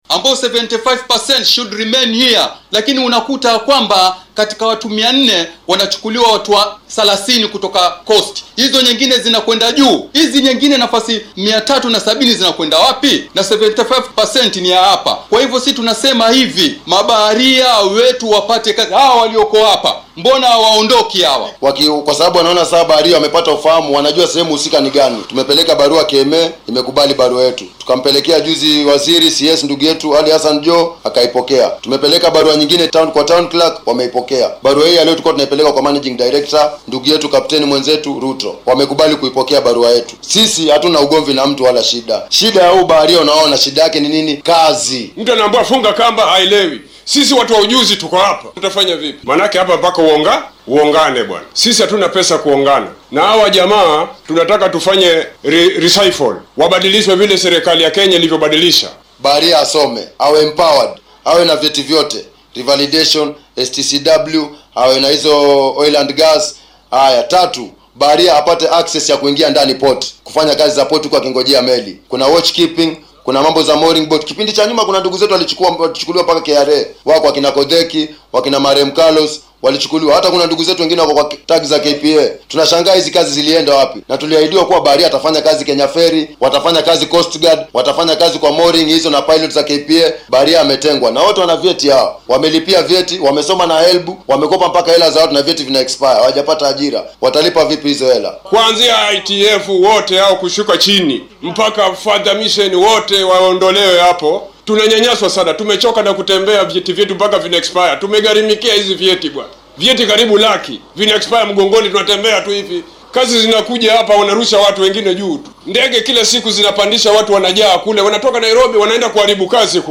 Badmaxayaasha ayaa sheegay in qaar ka mid ah hoggaamiyayaasha ururrada difaaca xuquuqda badmaaxiinta ay dayaceen shaqadooda. Qaar ka mid ah oo warbaahinta la hadlay ayaa dareenkooda muujiyay.